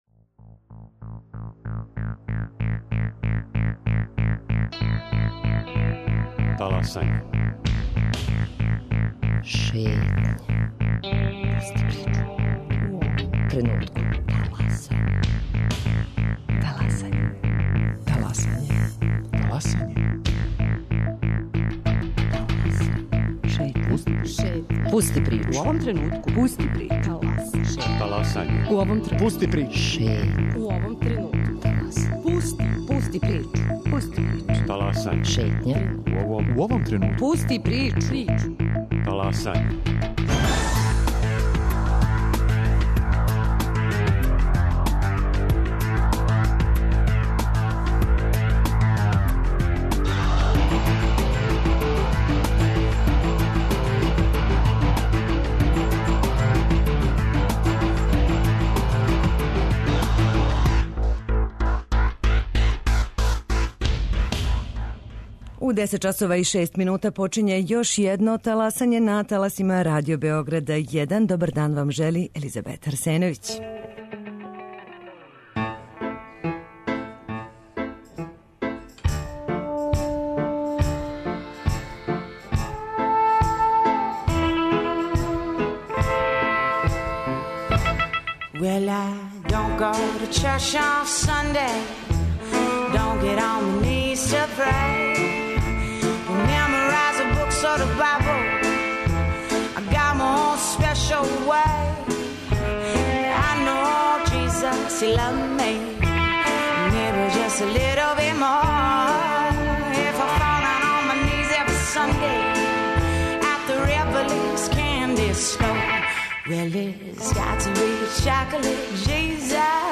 Овога пута представља усног хармоникаша који у једном подземном пролазу у центру Београда свира тако да се много ко заустави да би га чуо.